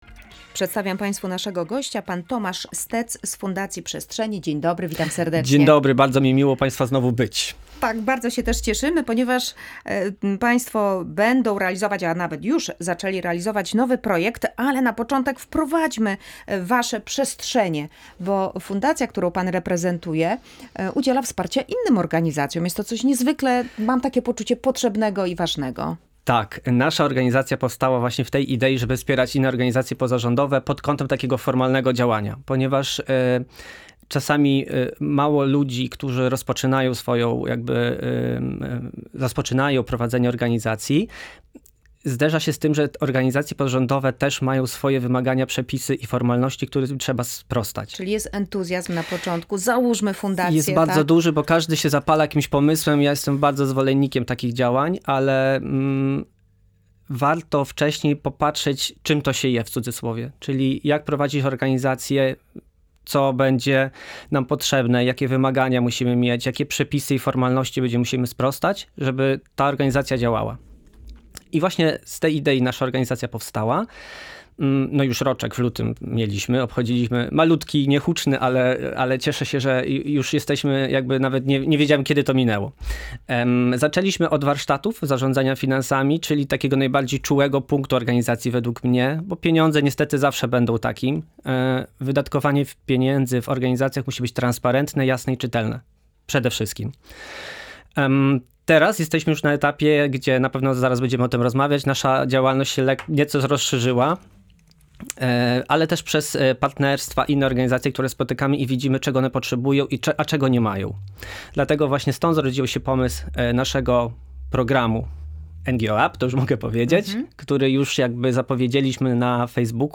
W audycji „Tu i Teraz” rozmawialiśmy o projekcie NGO UP dla organizacji pozarządowych, realizowanym przez Fundację Przestrzenie. Chodzi o bardzo konkretne wsparcie, dzięki któremu organizacje będą mogły jeszcze lepiej działać.